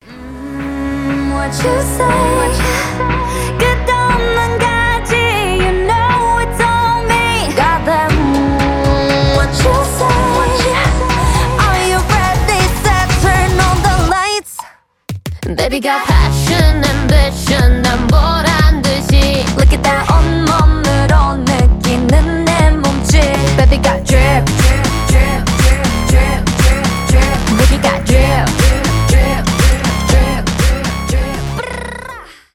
k-pop
поп